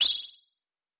Magic_v5_wav.wav